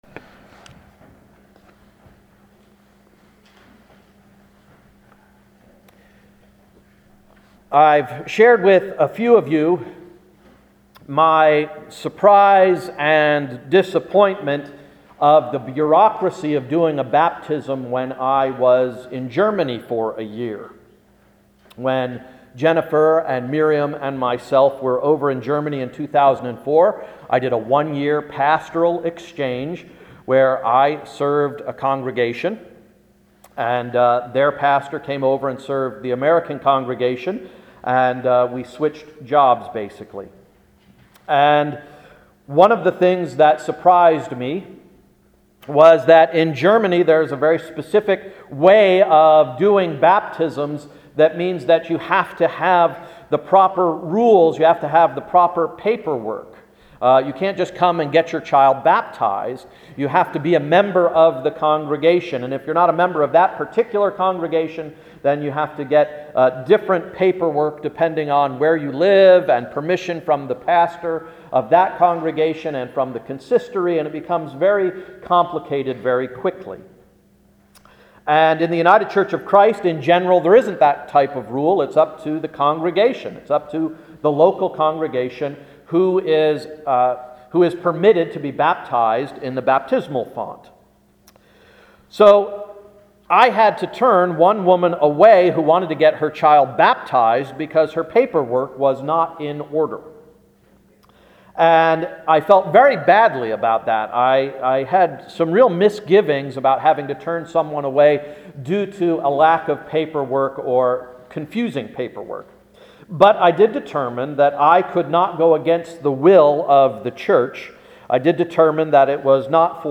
Sermon of January 9th, 2011–“Big Hearted Baptism”